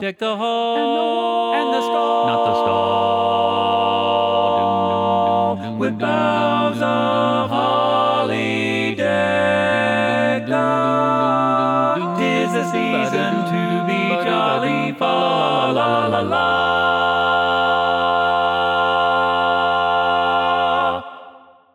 Key written in: F Major
How many parts: 4
Type: Barbershop
All Parts mix: